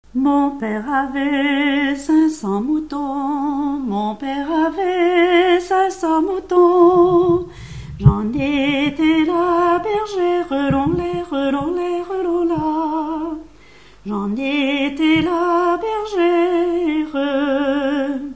Mémoires et Patrimoines vivants - RaddO est une base de données d'archives iconographiques et sonores.
Genre laisse
Enquête Arexcpo en Vendée-C.C. Moutiers-les-Mauxfaits